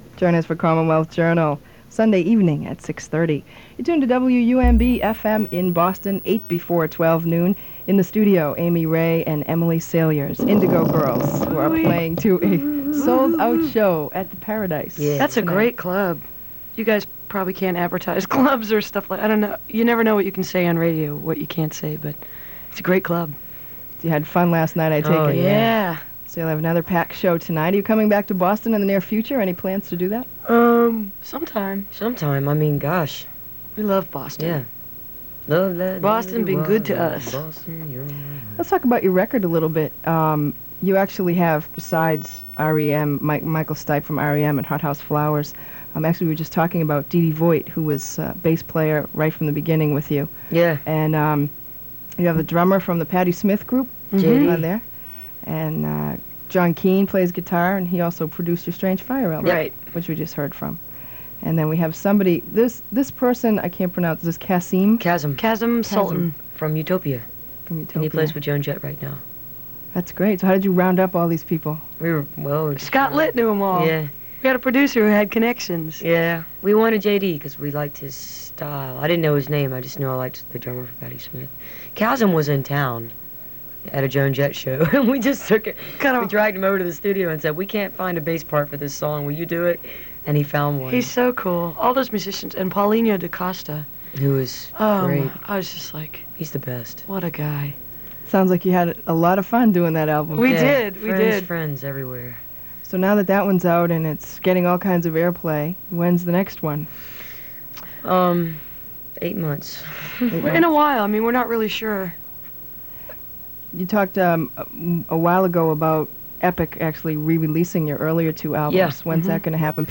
04. interview (4:51)